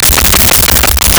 The Main Reactor Loop 01
The Main Reactor Loop 01.wav